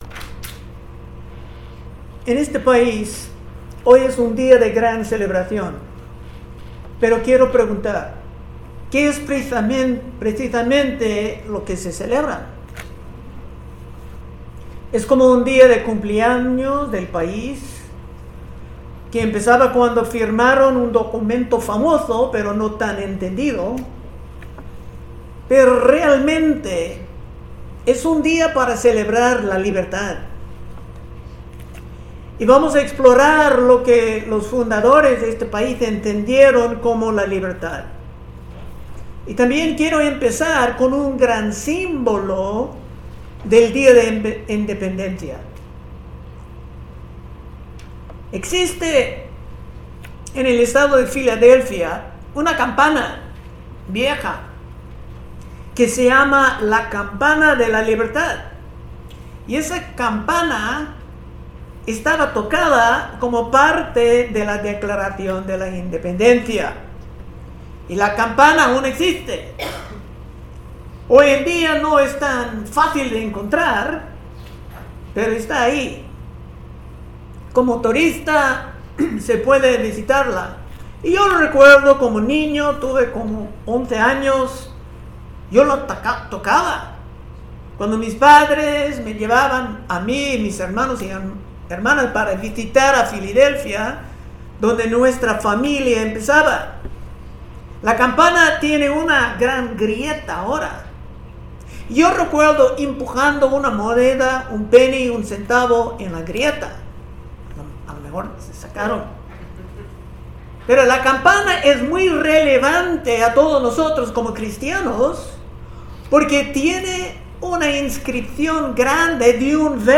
Predicaciones De Temas Generales